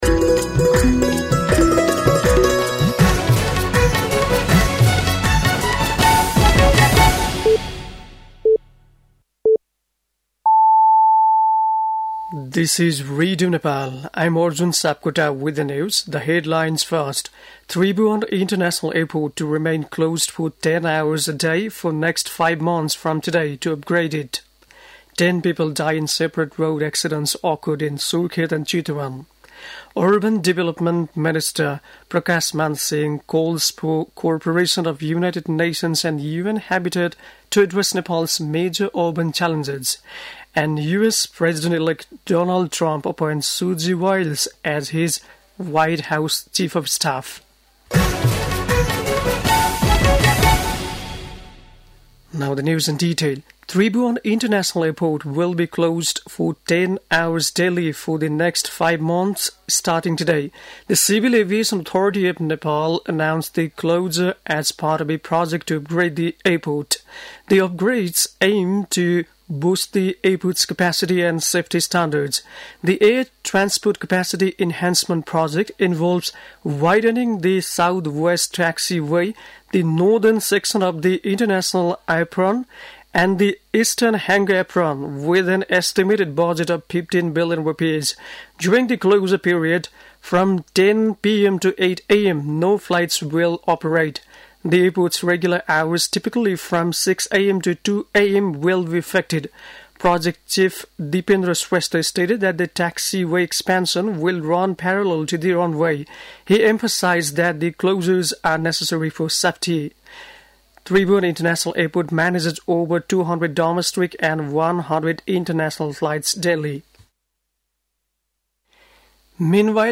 An online outlet of Nepal's national radio broadcaster
बेलुकी ८ बजेको अङ्ग्रेजी समाचार : २४ कार्तिक , २०८१